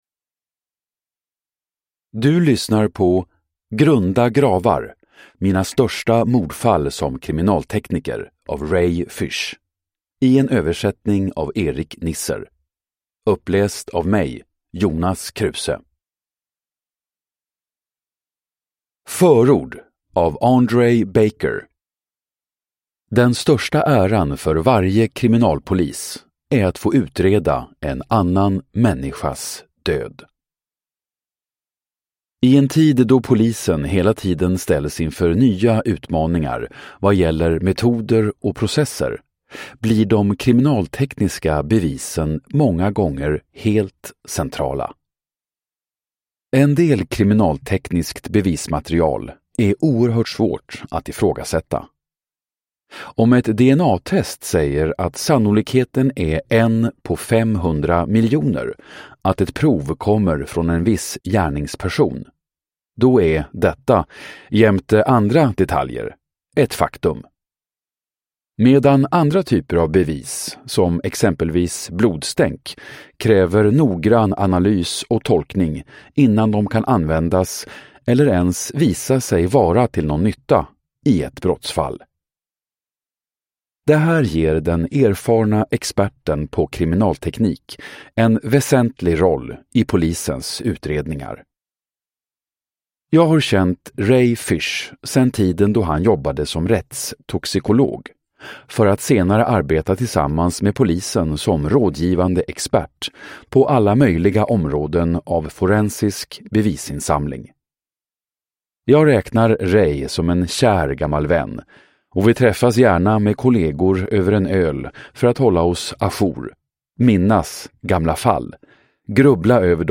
(ljudbok)